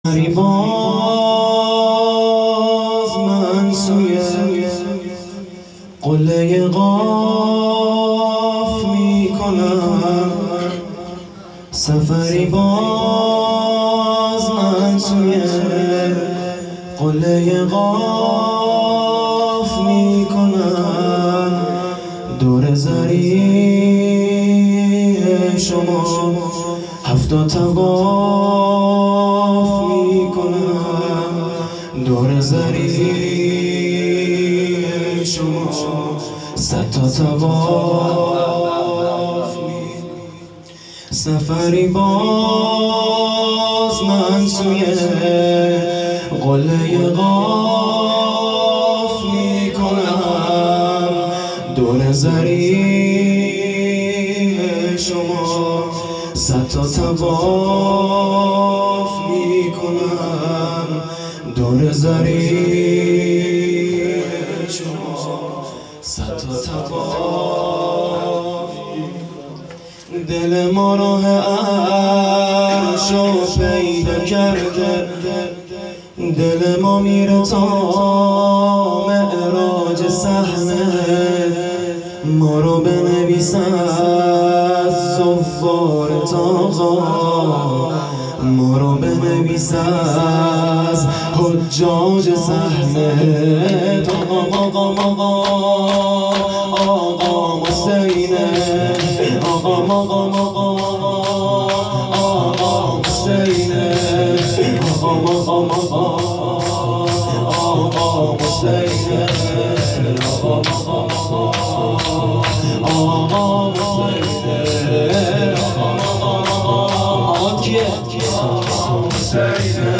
خیمه گاه - هیئت المهدی(عج) - شور شهادت امام علی ع